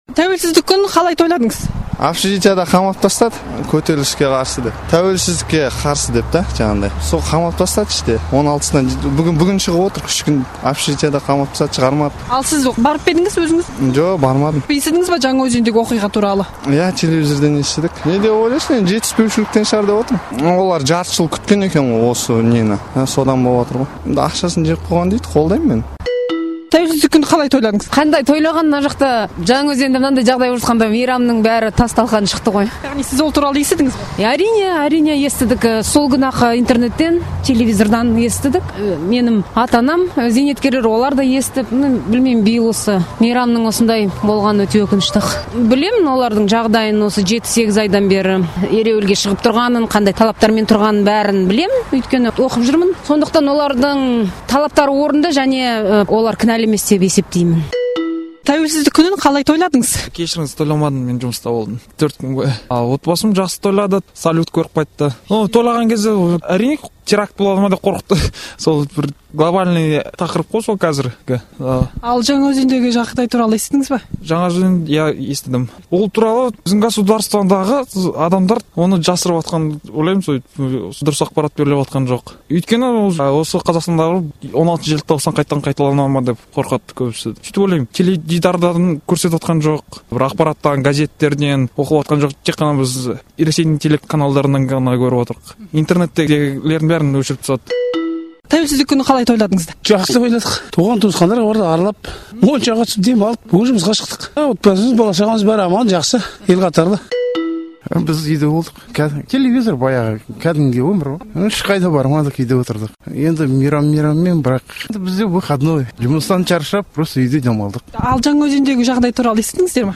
Азаттық тілшісі Алматы тұрғындарына жолығып, Қазақстан тәуелсіздігінің 20 жылдық мейрамын қалай өткізгендерін сұрай келе, Жаңаөзендегі оқиға жайлы пікірлерін білген еді.